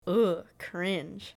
Download Cringe sound effect for free.